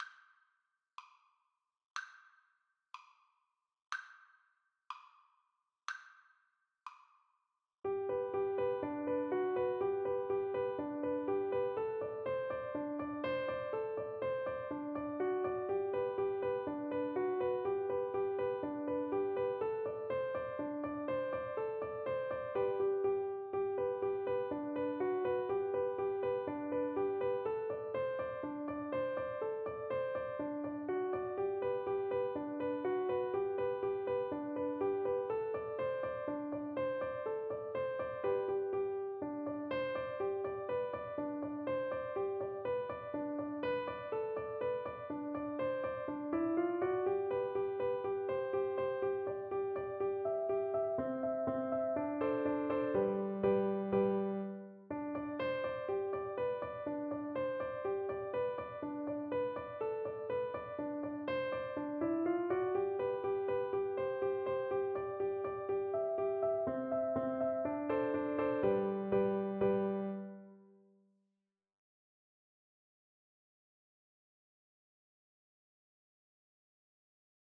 Flute
G major (Sounding Pitch) (View more G major Music for Flute )
2/4 (View more 2/4 Music)
Traditional (View more Traditional Flute Music)
Hornpipes for Flute